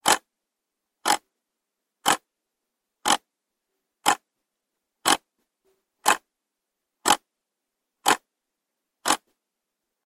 Звук часов рядом с шахматами